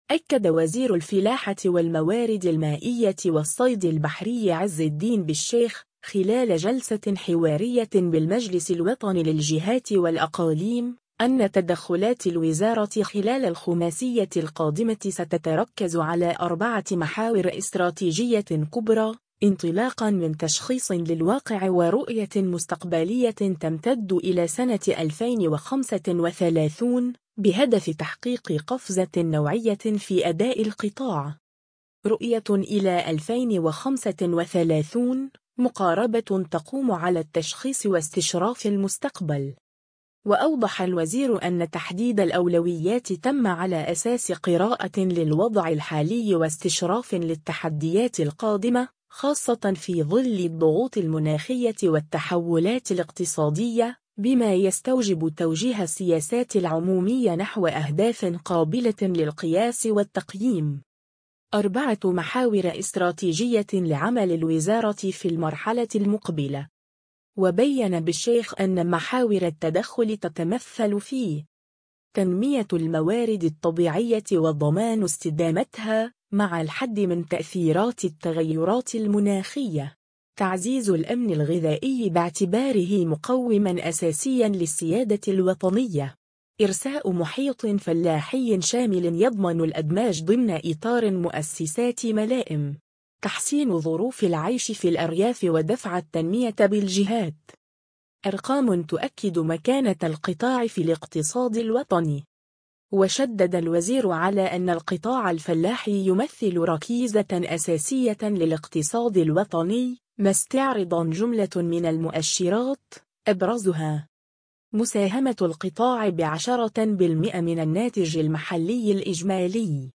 أكد وزير الفلاحة والموارد المائية والصيد البحري عز الدين بالشيخ، خلال جلسة حوارية بالمجلس الوطني للجهات والأقاليم، أن تدخلات الوزارة خلال الخماسية القادمة ستتركز على أربعة محاور استراتيجية كبرى، انطلاقًا من تشخيص للواقع ورؤية مستقبلية تمتد إلى سنة 2035، بهدف تحقيق قفزة نوعية في أداء القطاع.